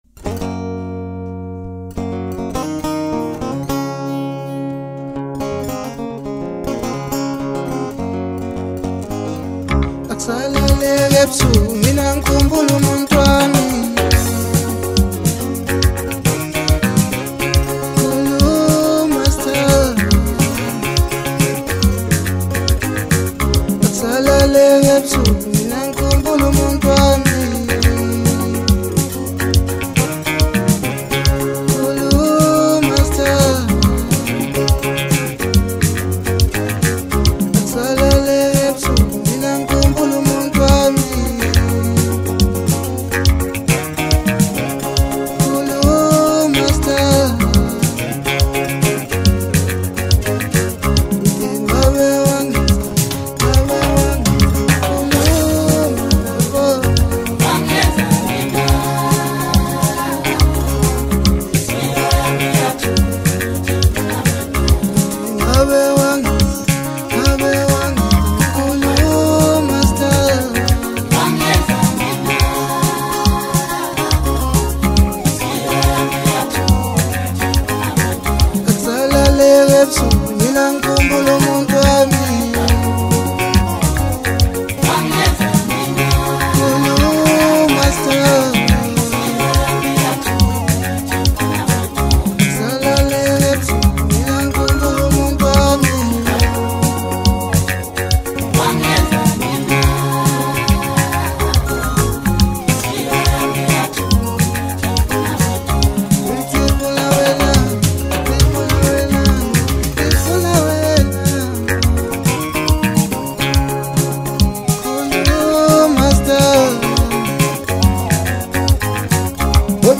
Home » Maskandi » DJ Mix » Hip Hop
South African singer-songwriter